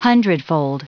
Prononciation du mot hundredfold en anglais (fichier audio)
Prononciation du mot : hundredfold